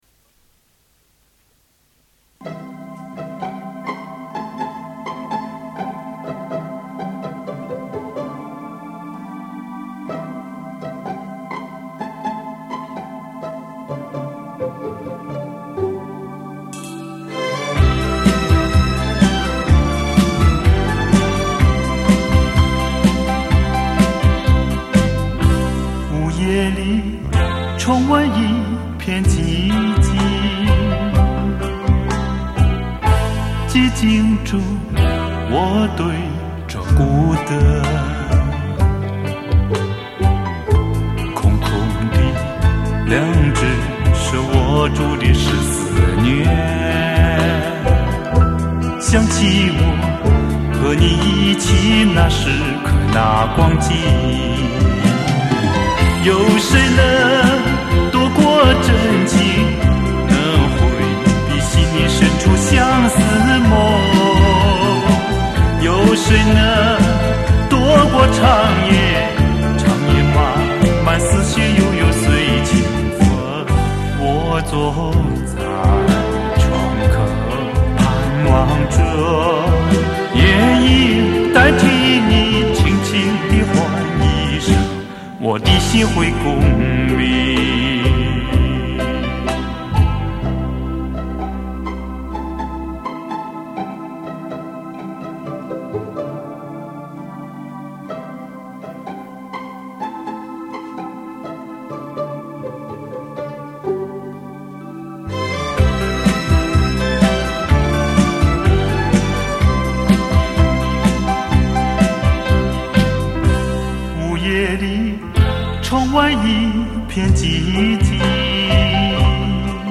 磁带转WAV分轨